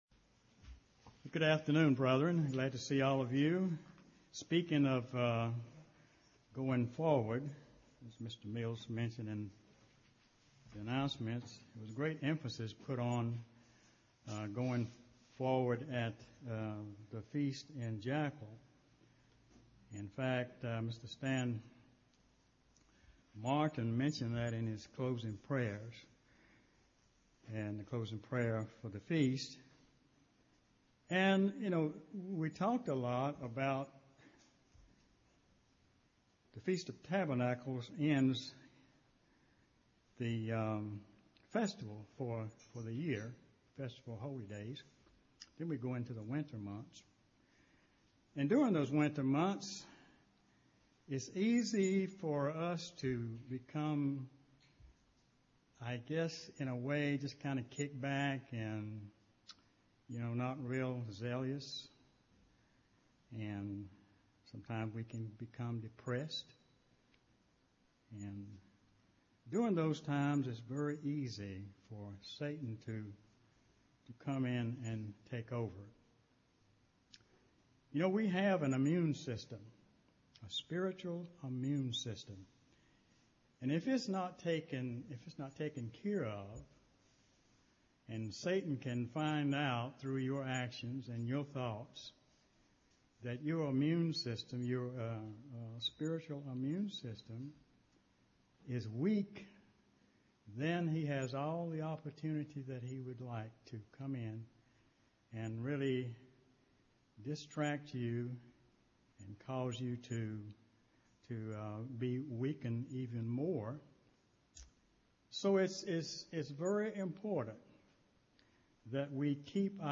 Given in Greensboro, NC
UCG Sermon Studying the bible?